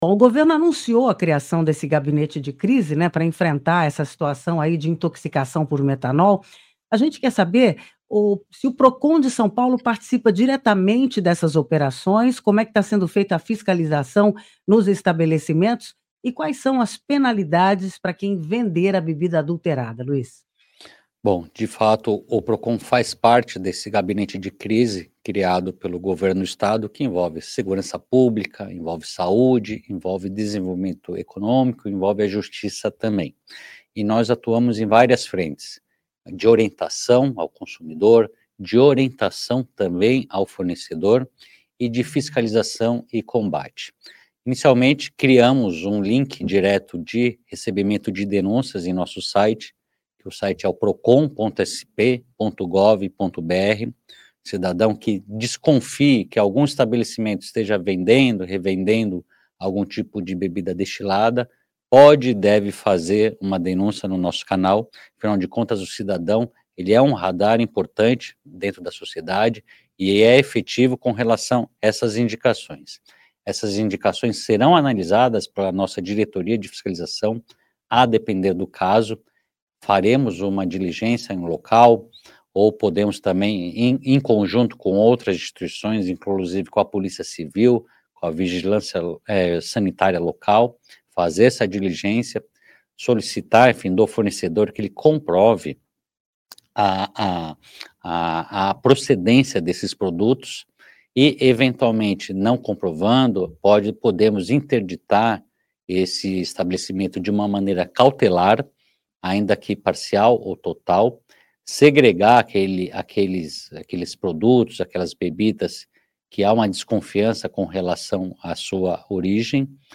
Hoje ,recebemos no Conexão Saudade o diretor executivo do Procon-SP, Luiz Orsatti Filho. Ele fala sobre as medidas do Governo de São Paulo no combate à intoxicação por metanol, que já mobilizou um gabinete de crise, prisões de suspeitos e a apreensão de 50 mil garrafas adulteradas.